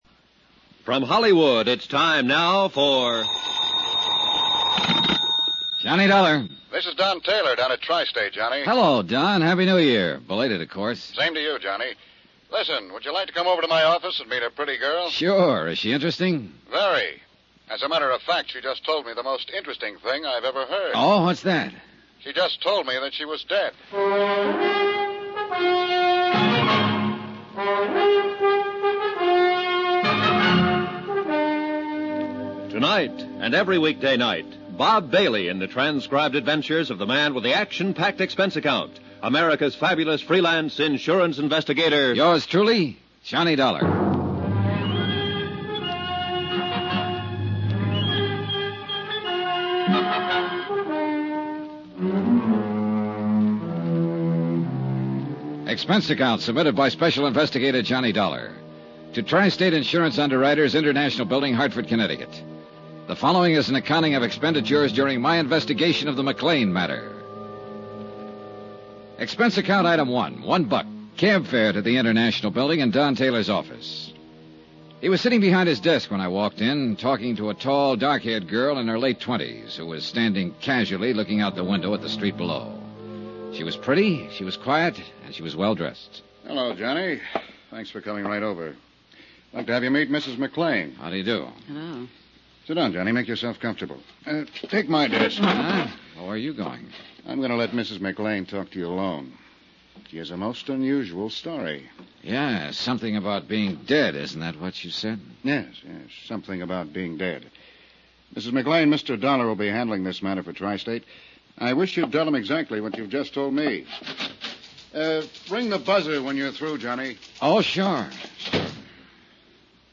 Yours Truly, Johnny Dollar Radio Program, Starring Bob Bailey